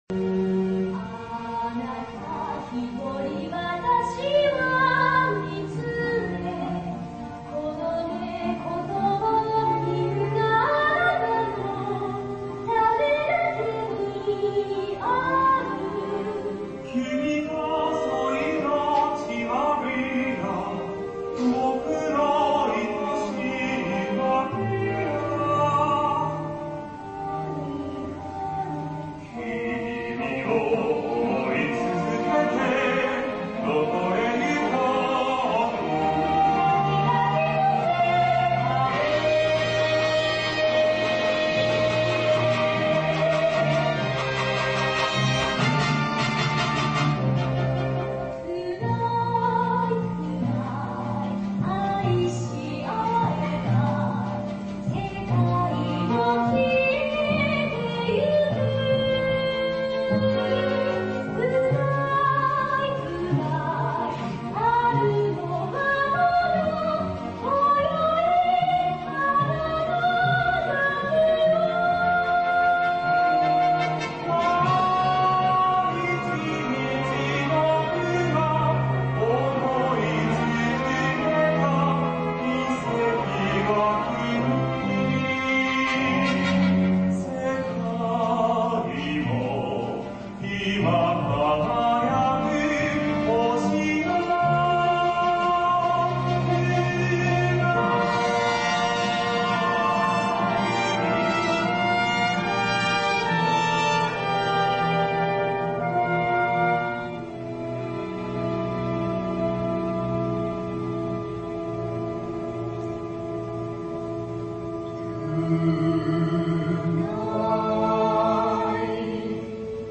ちょっとアースのＣＭの歌に通じるところ（のーてんきそうな所）があるにゃあ・・・